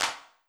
TEC Clap.wav